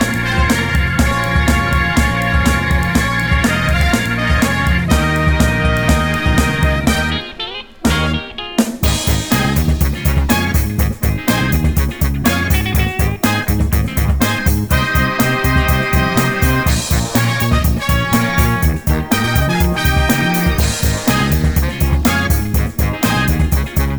One Semitone Up R'n'B / Hip Hop 3:16 Buy £1.50